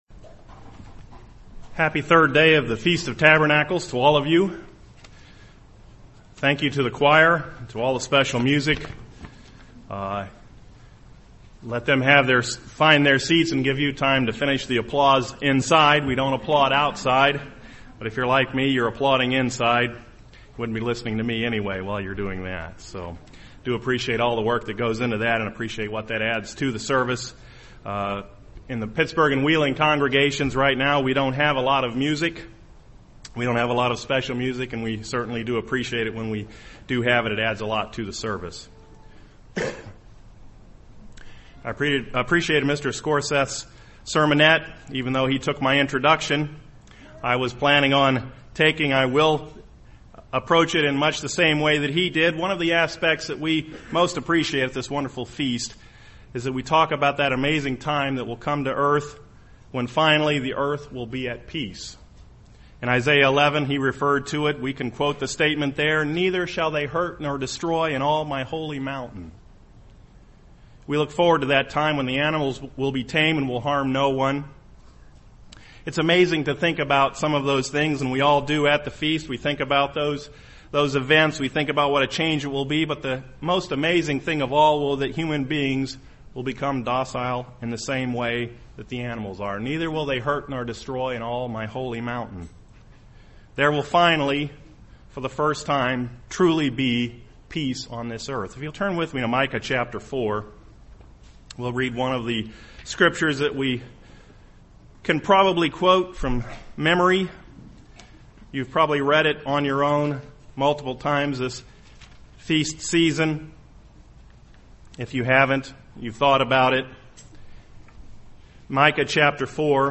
This sermon was given at the Lake George, New York 2012 Feast site.